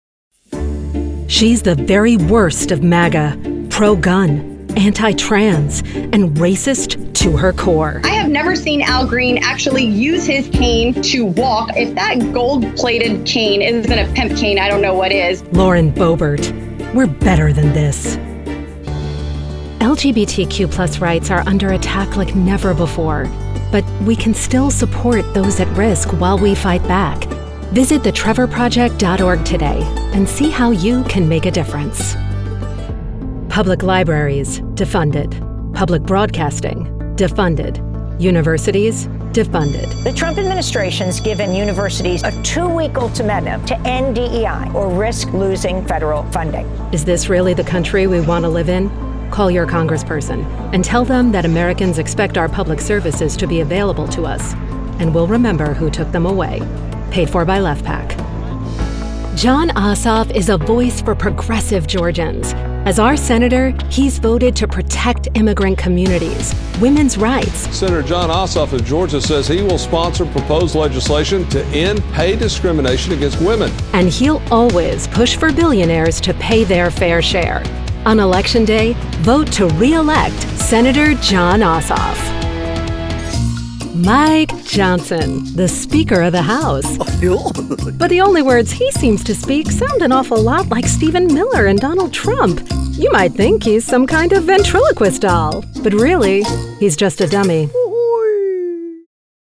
Female
Adult (30-50), Older Sound (50+)
Democratic Spots
Words that describe my voice are Conversational, Warm, Trustworthy.
All our voice actors have professional broadcast quality recording studios.